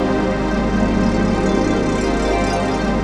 CHRDPAD016-LR.wav